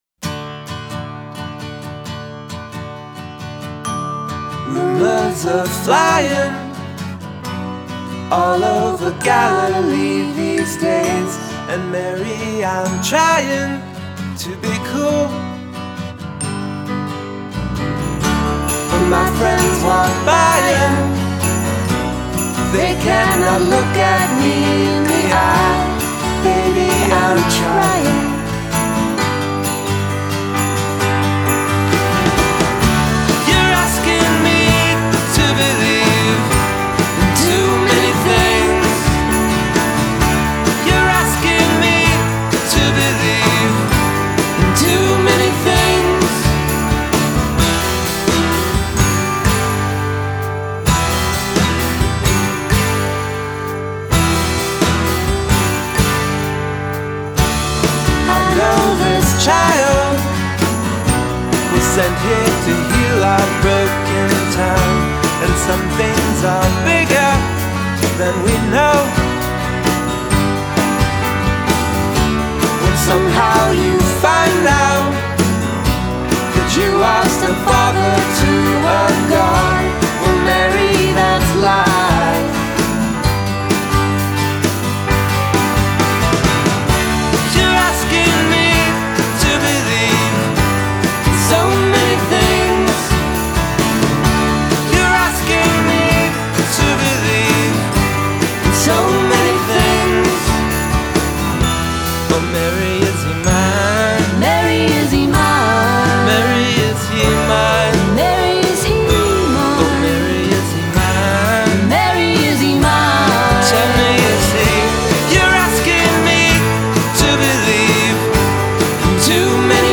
” a new holiday, sing-along classic.